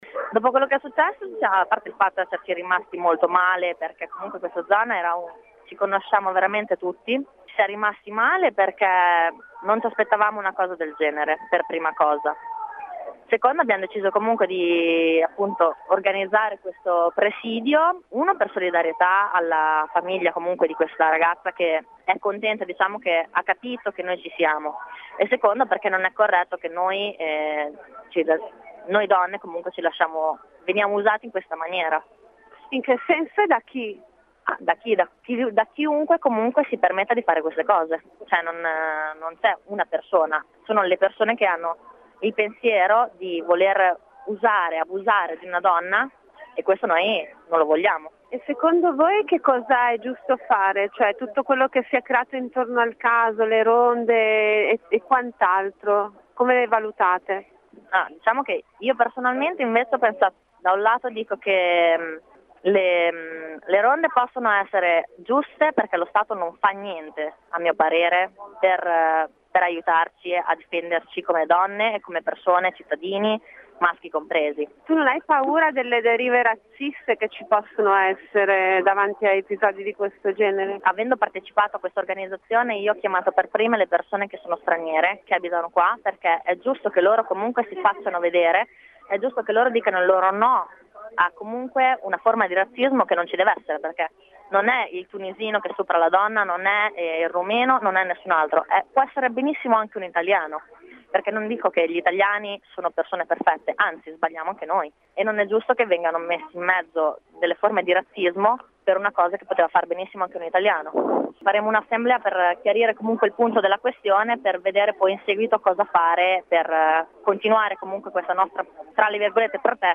21 feb. – Un centinaio di persone, soprattutto gli abitanti del quartiere, ha aderito al presidio indetto per oggi pomeriggio dalle ragazze e dai ragazzi del Centro polivalente Mattei-Martelli.